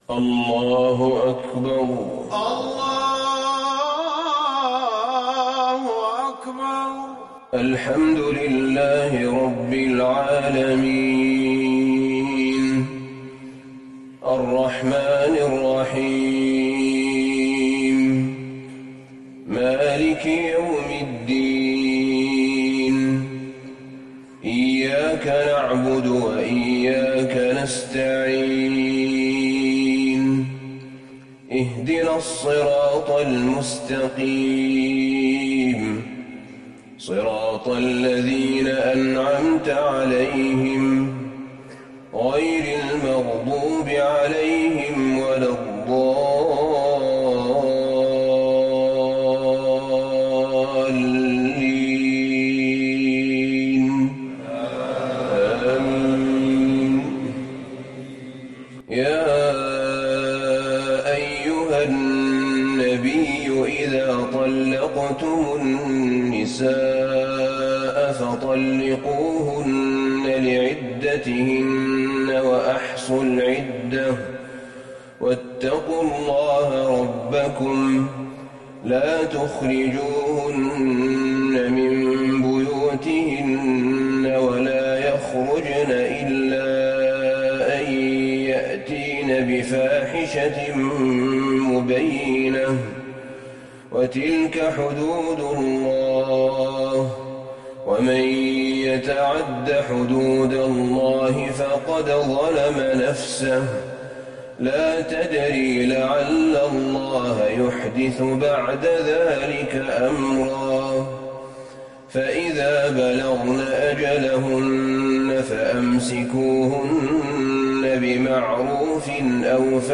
صلاة الفجر للشيخ أحمد بن طالب حميد 20 جمادي الأول 1441 هـ
تِلَاوَات الْحَرَمَيْن .